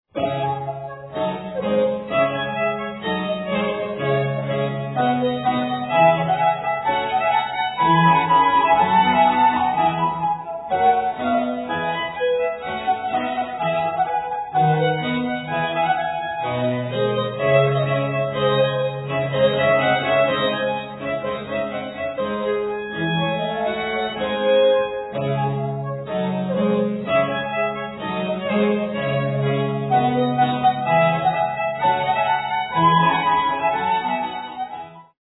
Sonata for 2 violins (or flutes) & continuo in D minor